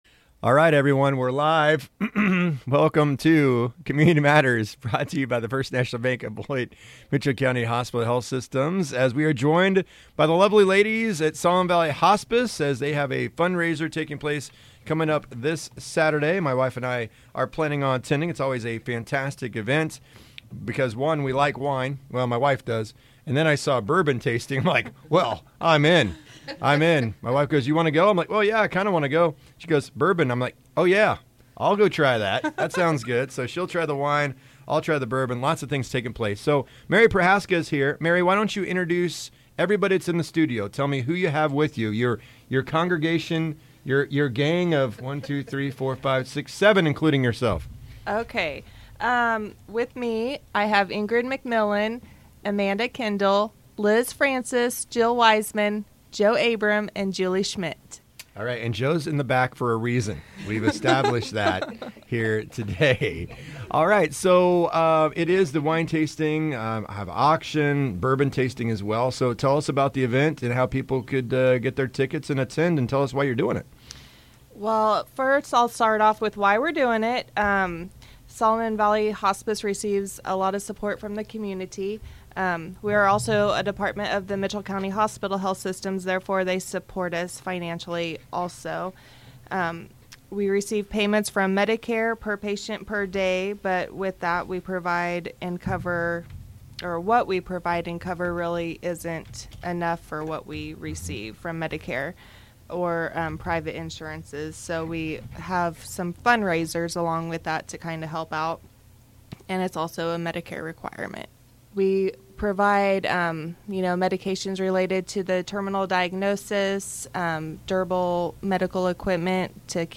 The ladies at Solomon Valley Hospice join us to share info about their annual wine tasting and silent auction this Saturday in Beloit.